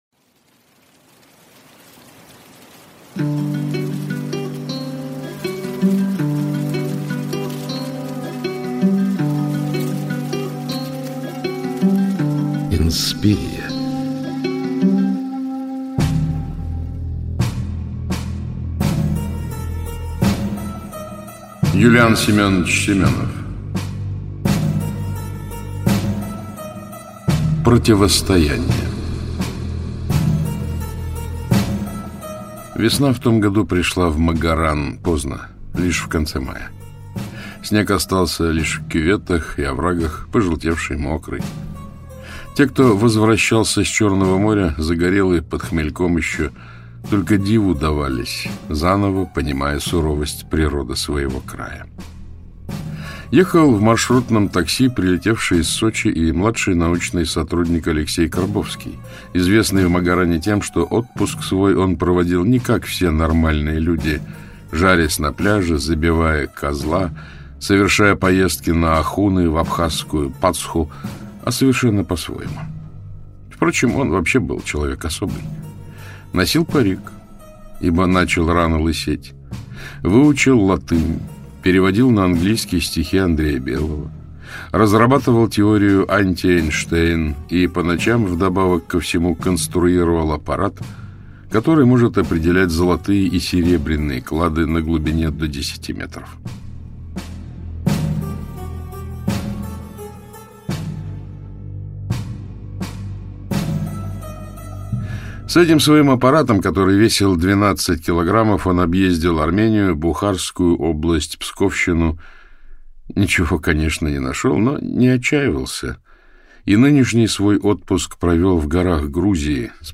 Читает: Александр Клюквин
Аудиокнига «Противостояние».